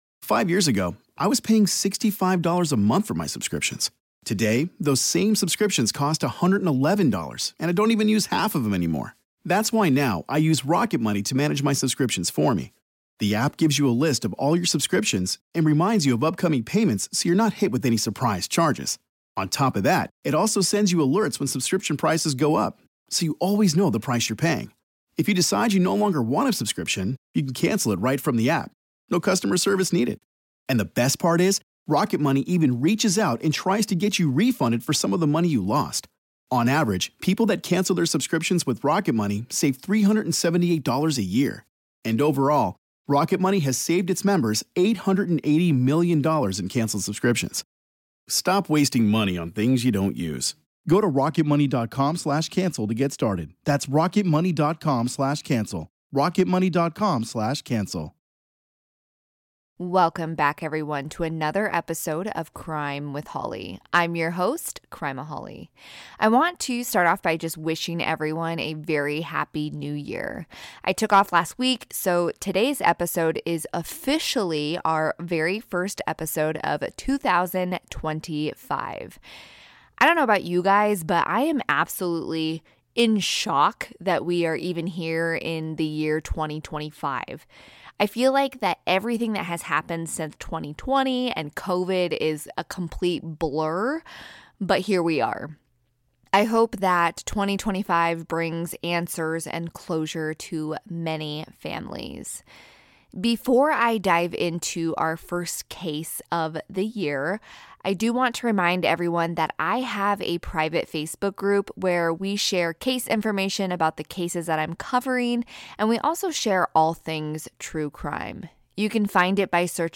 New introduction and ending have since been recorded and added, audio levels may reflect the different recordings.